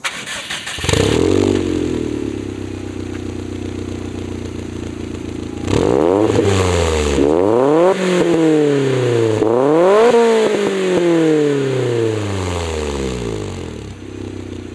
ボゥヲゥヲゥヲゥ・・・って感じの音の中にドドドが混ざったような音に変わっています。
回すとボゥヲゥヲゥ→フォォ〜ンと言う感じで、排気干渉の無いやや乾いた音を発しながらチタンマフラーと相まってかなりいい音がします。
BAKUマニ+フジツボRM-01A Tiレーシングサウンド.aif
低音をカットした訳ではありません。(念のため)
FLATTマニの録音時と同じくらいの距離で録音しました。
マフラー出口斜め後方1mくらい。